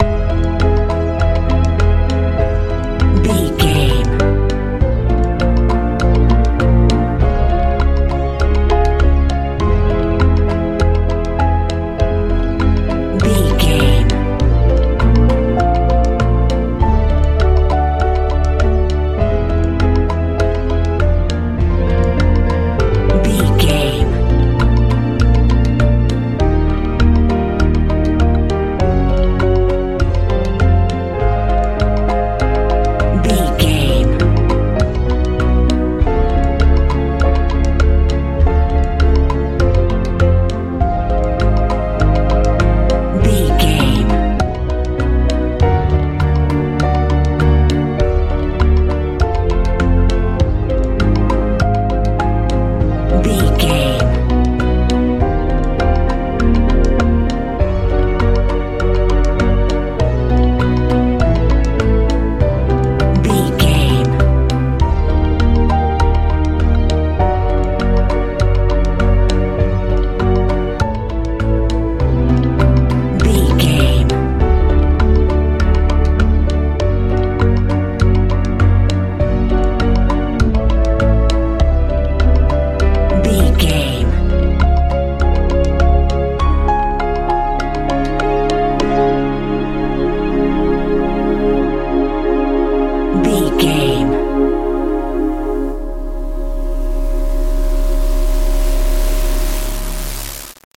new age feeling music
Ionian/Major
B♭
strange
mystical
piano
synthesiser
bass guitar
drums
suspense
haunting
dramatic